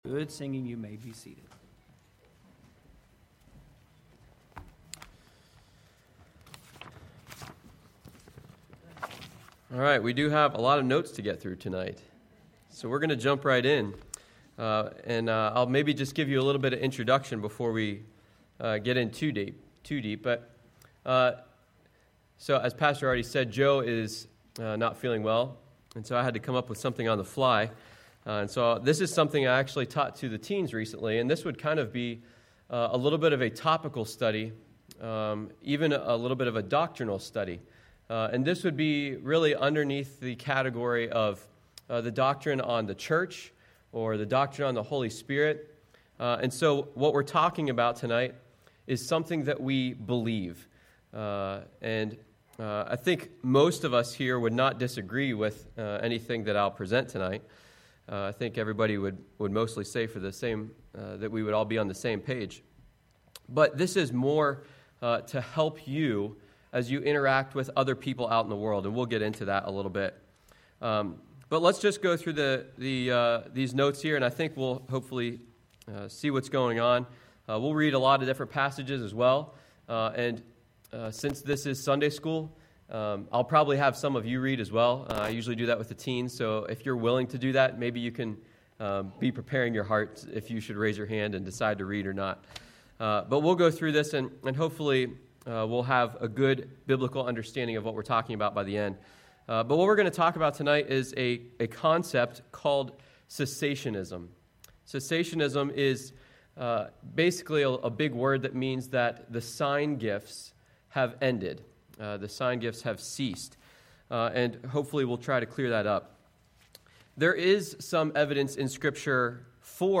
Sermons by Bluegrass Baptist Church